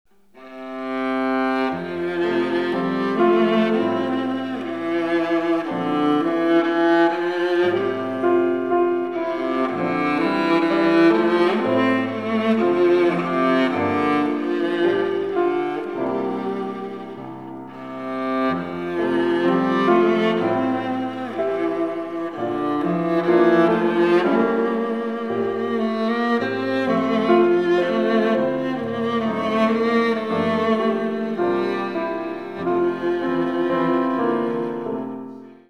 ヴァイオリン